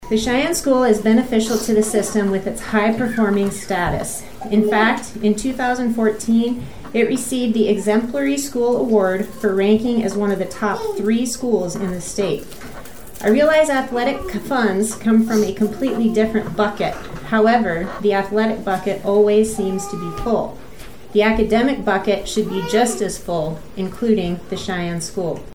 During the public comment portion, about a dozen residents– all of them opposed– addressed the possibility of closing the school.